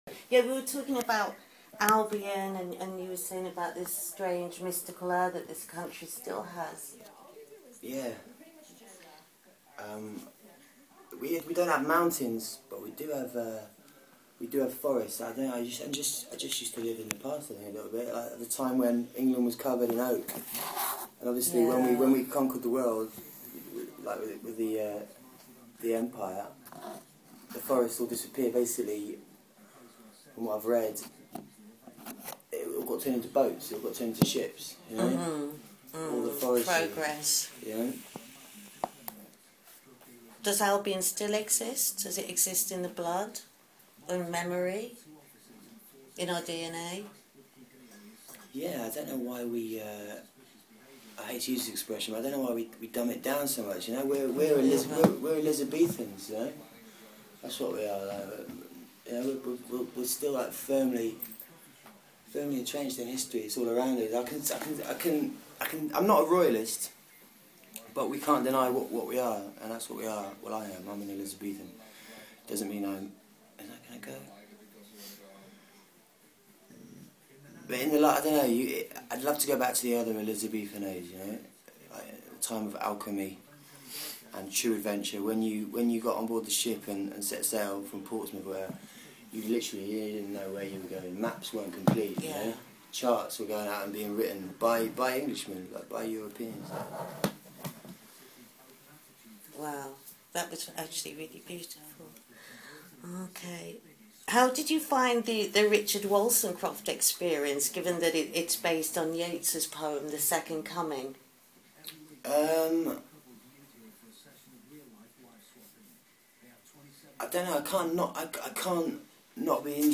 pd-interview.mp3